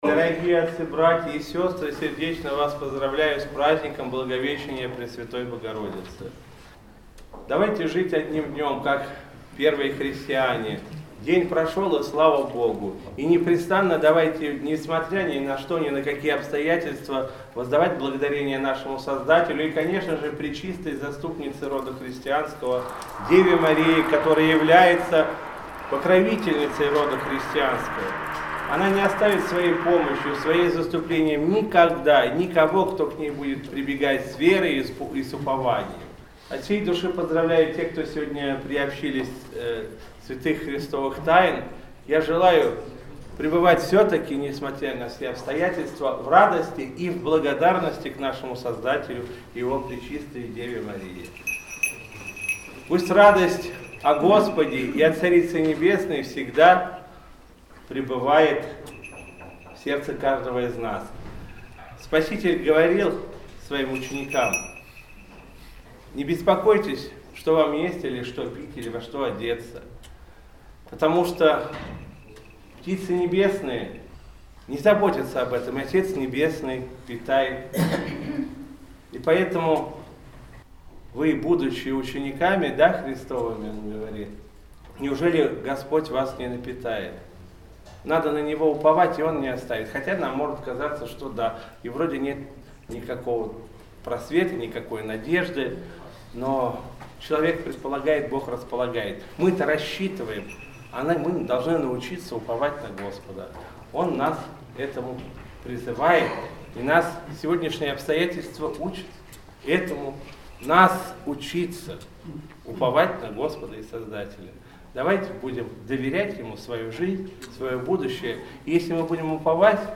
7 апреля 2020 года, в праздник Благовещения Пресвятой Богородицы, день преставления святителя Тихона, Патриарха Московского и всея России, митрополит Вологодский и Кирилловский Игнатий совершил вечерню и Божественную литургию святителя Иоанна Златоуста в Воскресенском кафедральном соборе г.Вологды.
Митрополит Игнатий обратился к присутствующим с архипастырским словом.